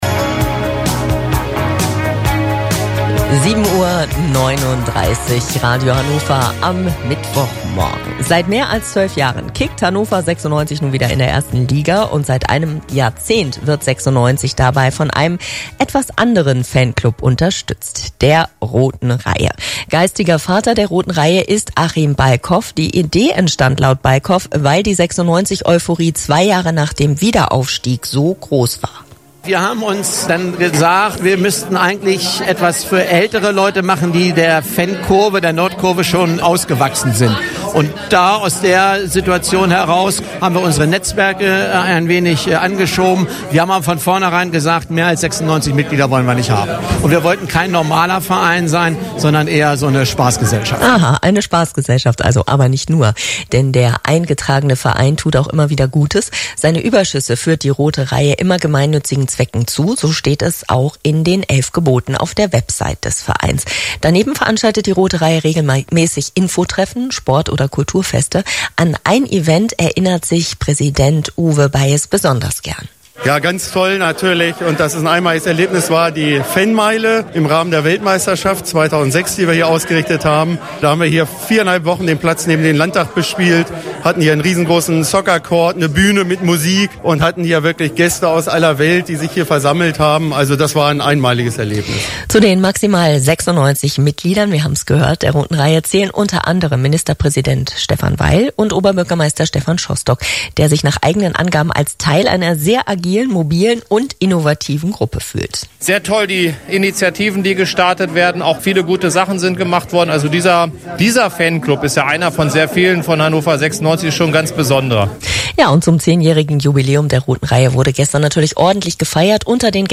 Und dazu gibt es einen Live-Mitschnitt von Radio Hannover, die ebenfalls über das grandiose Jubiläum berichtet haben.